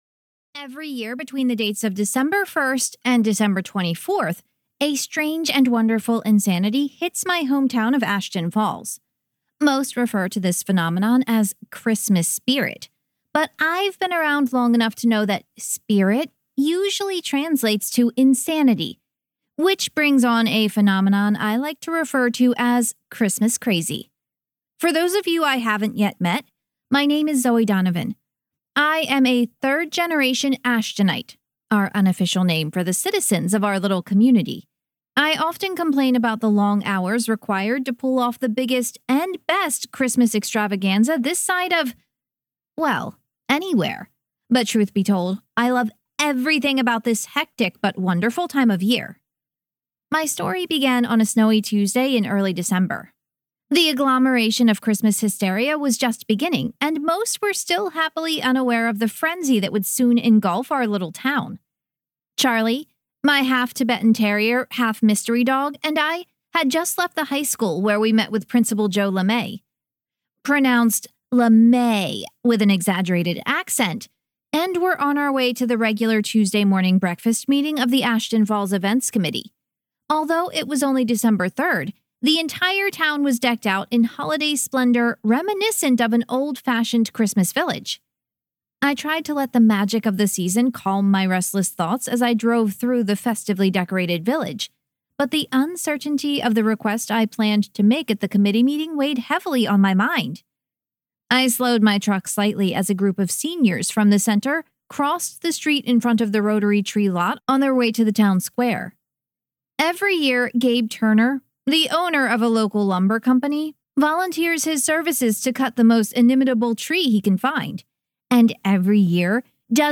• Audiobook
Book 3 Retail Audio Sample Christmas Crazy Zoe Donovan Mystery.mp3